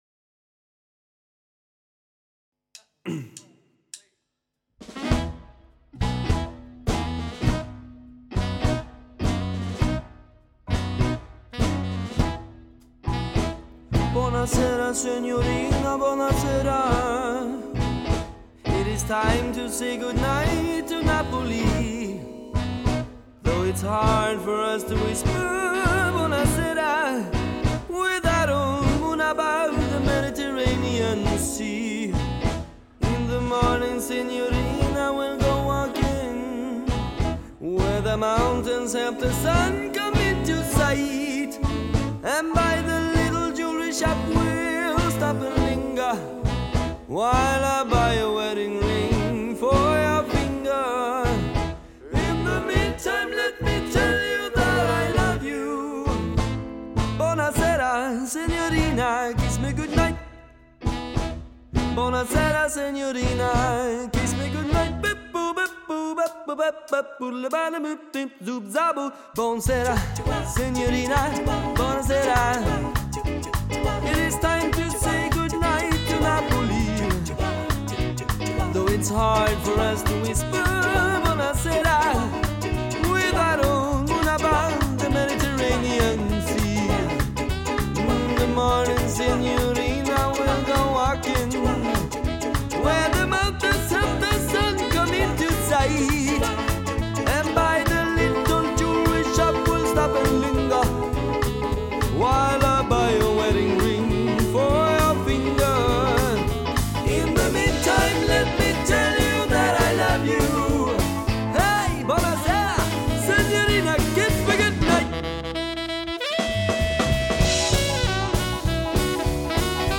Música de Surf
guitarra/voz
bajo
saxofón
batería/voz Grabado en Big Man Studio, Stockholm 2017